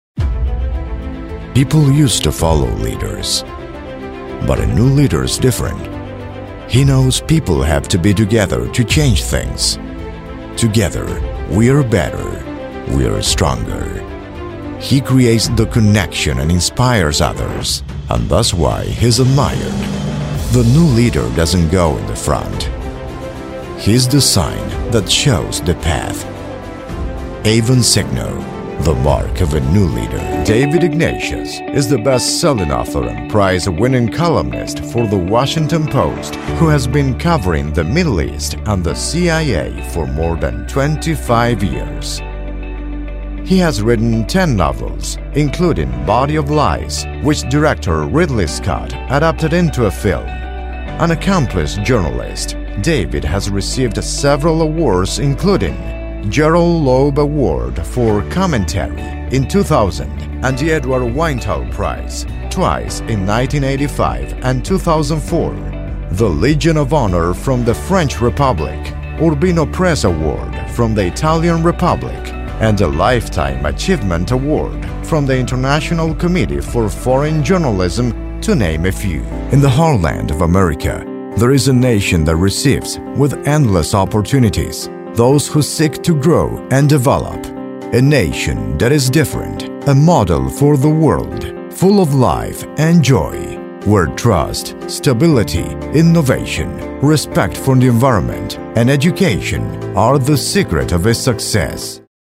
LOCUTOR PREMIUM
OBSERVACIONES: Voz versátil para todo tipo de grabaciones en diversos idiomas.
DEMO INGLÉS: